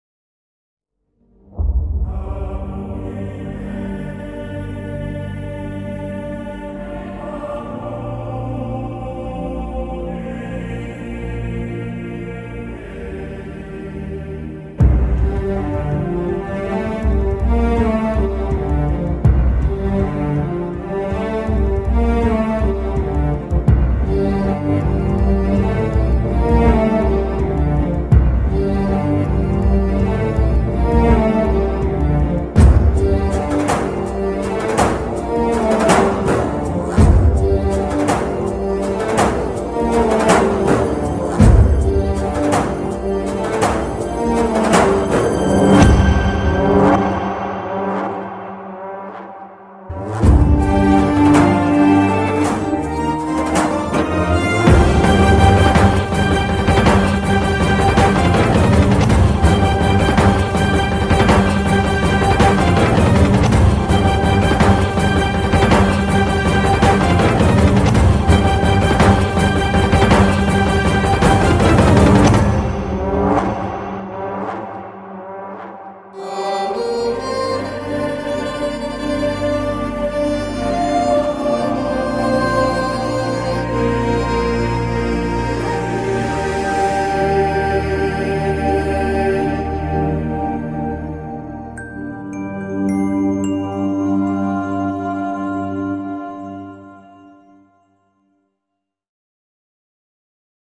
without dialogues and disturbing sounds
instrumental music